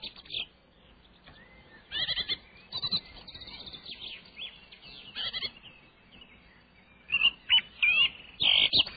169ambient02.wav